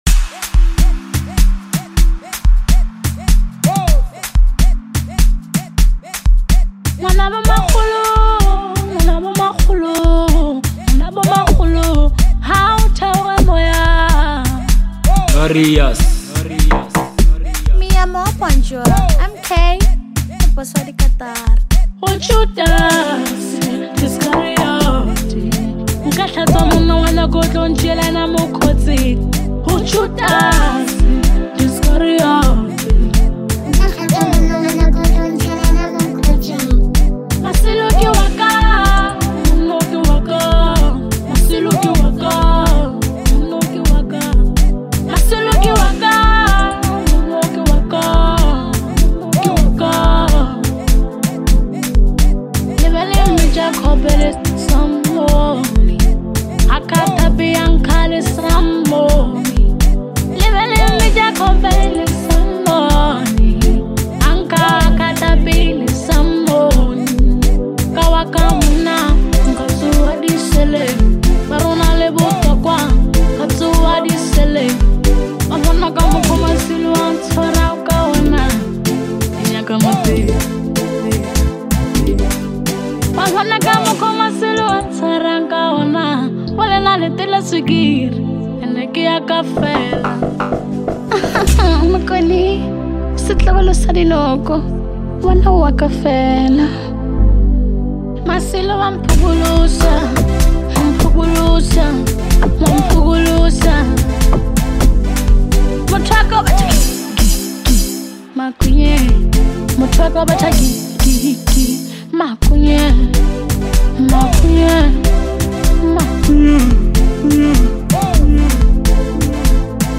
is a vibrant and empowering track
high energy melody
an irresistible beat